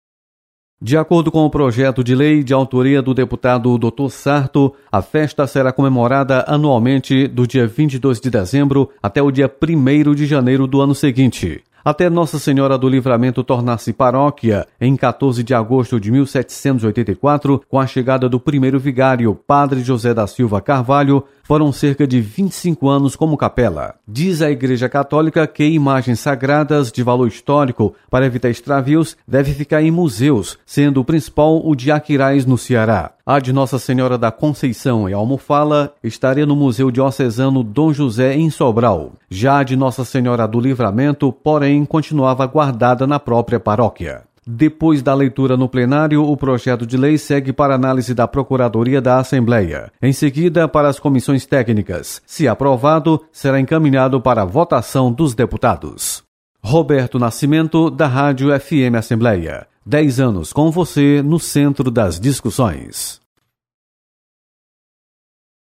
Projeto inclui no calendário oficial do Estado, a Festa de Nossa senhora do Livramento do município de Trairi. Repórter